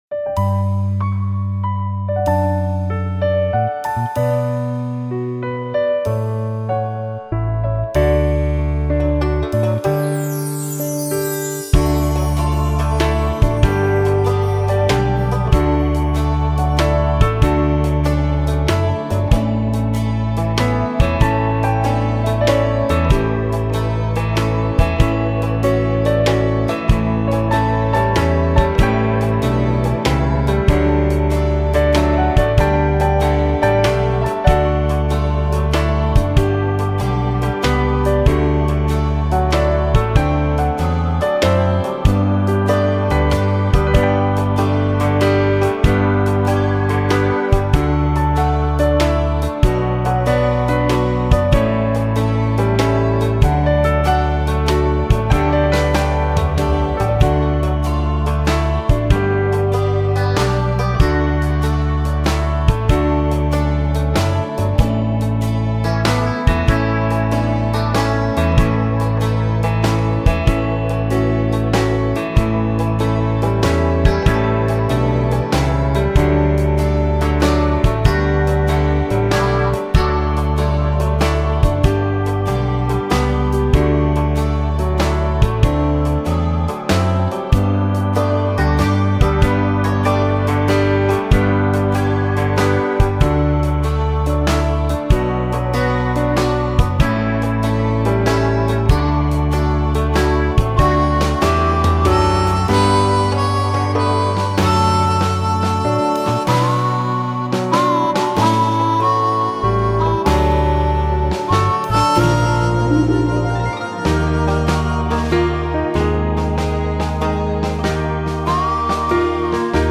HAVE-THINE-OWN-WAY-LORD-Karaoke-version-Key-D-E♭.mp3